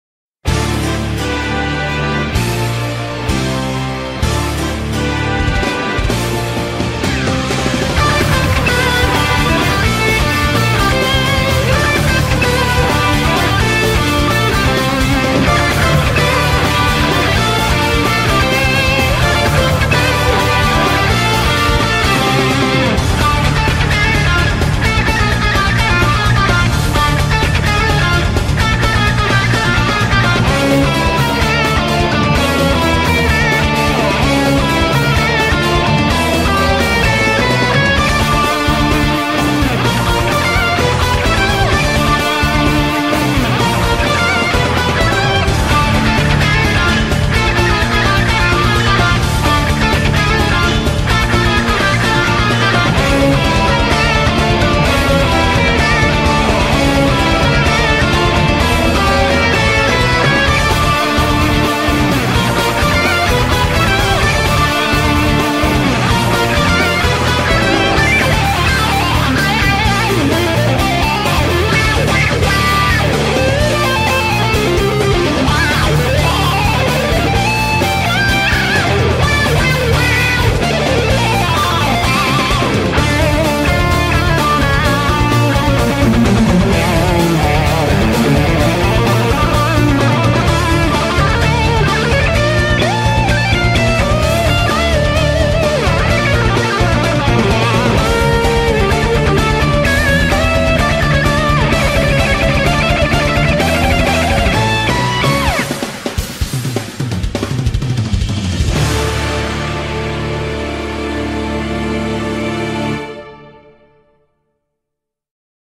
BPM128
Audio QualityPerfect (Low Quality)